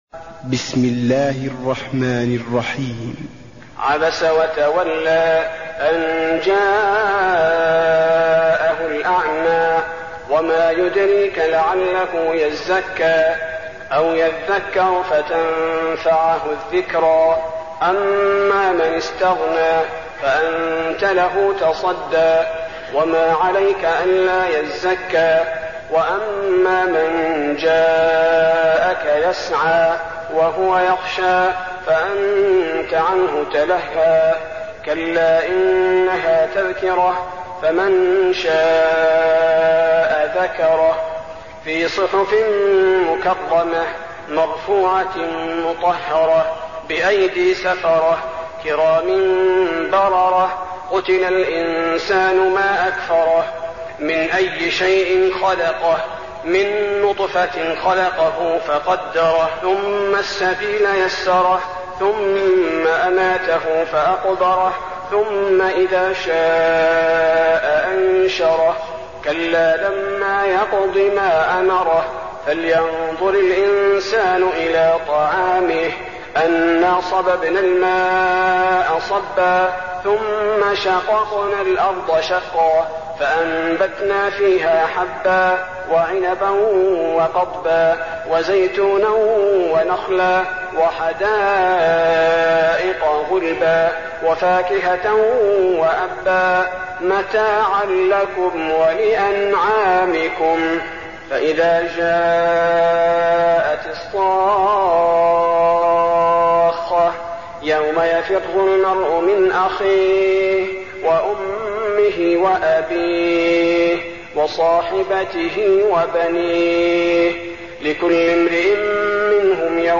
المكان: المسجد النبوي عبس